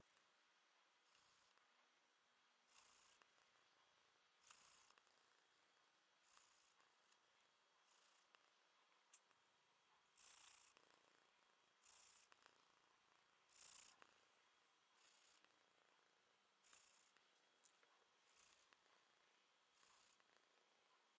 小猫在麦克风旁打呼噜
描述：小猫在麦克风旁打呼噜，睡得很香
标签： 打呼噜 呜呜叫 近距离
声道单声道